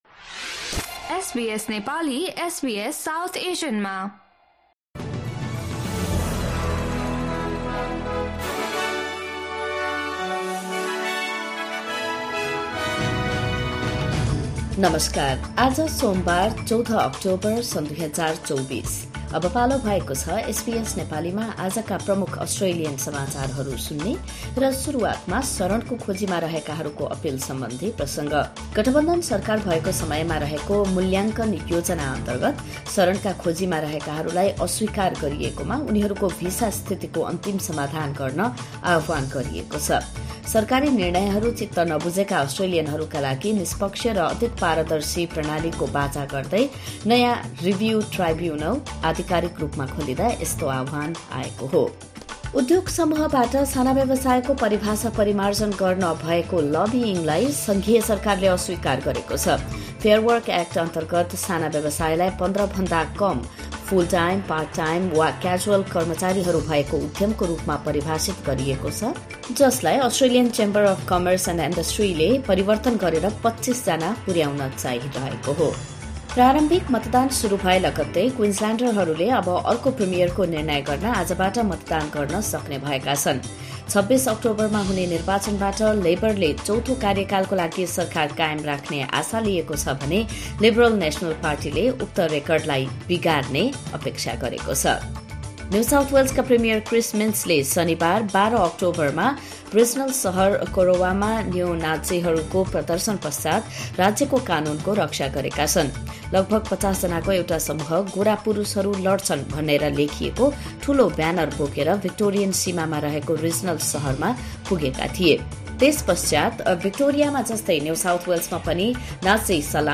SBS Nepali Australian News Headlines: Monday, 14 October 2024